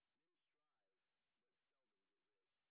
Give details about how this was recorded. sp06_white_snr20.wav